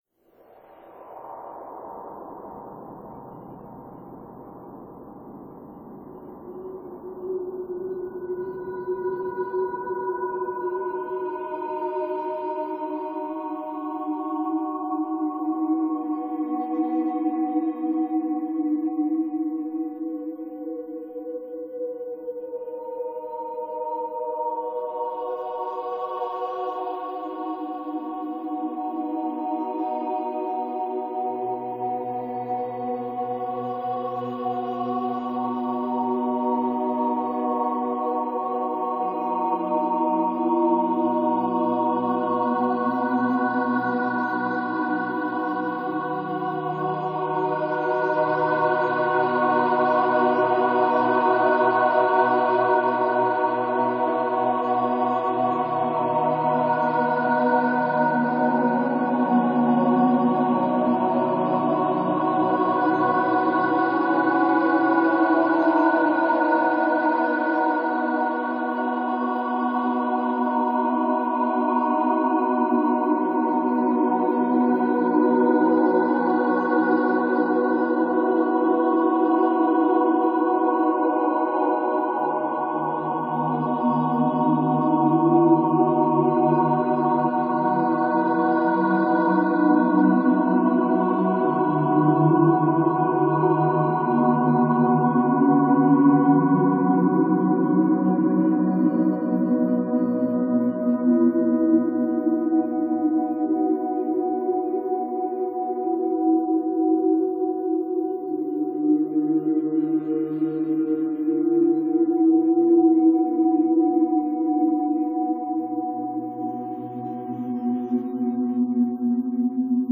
Arctic wind - very experimental instrumental.
I used synthesised choirs as a stand-in while I wrote it.